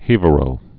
(hēvə-rō)